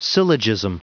Prononciation du mot syllogism en anglais (fichier audio)
Prononciation du mot : syllogism
syllogism.wav